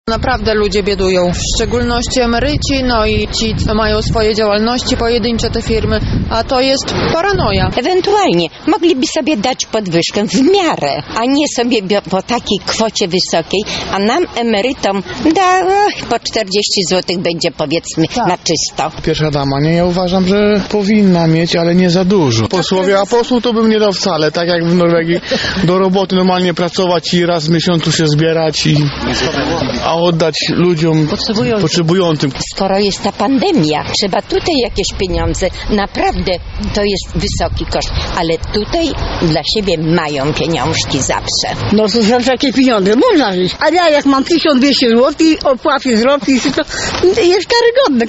Zapytaliśmy lublinian, co sądzą o tych zmianach:
Sonda uliczna